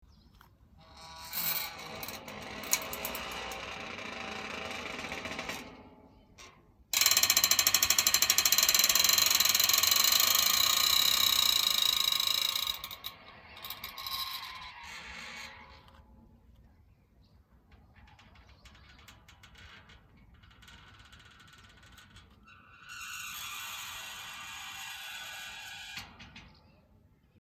Звуки калитки